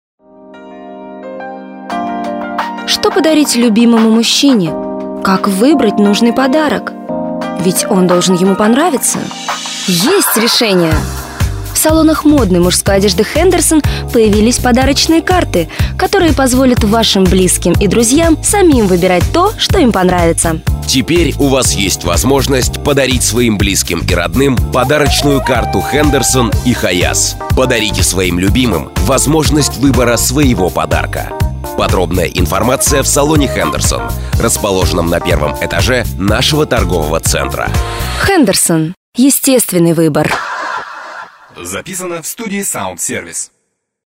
- это яркий увлекательный радиоролик, который записывается по ролям в виде диалога (покупателя с продавцом) или "показательной сценки" (участвуют профессиональные актеры), что, несомненно, выделяет его среди других радиороликов.
Примеры радиороликов Драматизация (mp3):